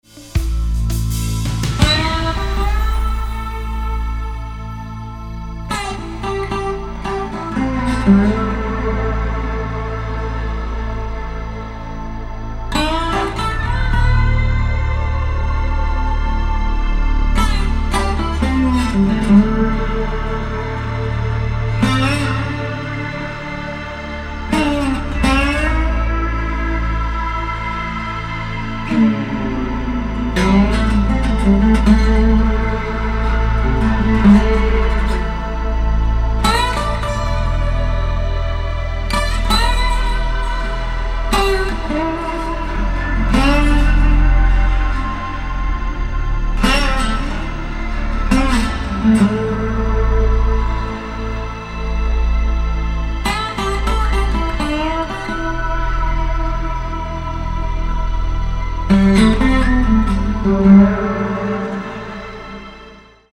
Tinged with blues and Cajun stylings